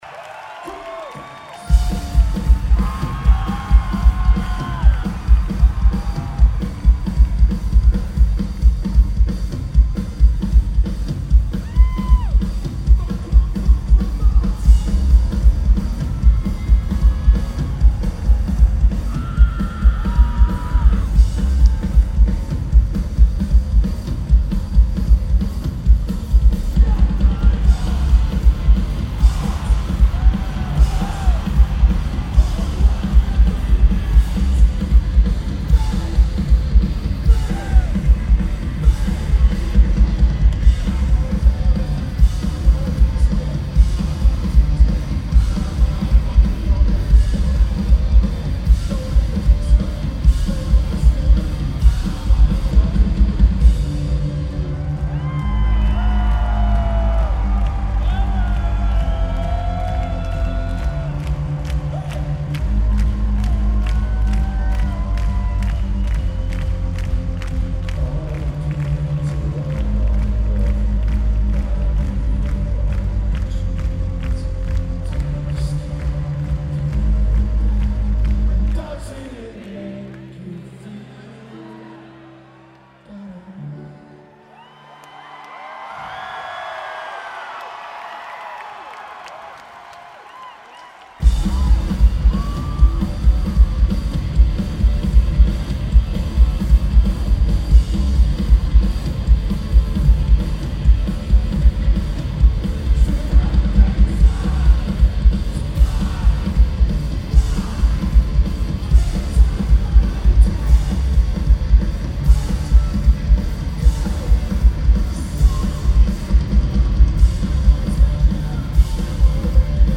XFINITY Center
Very good recording, recorded FOB/Center.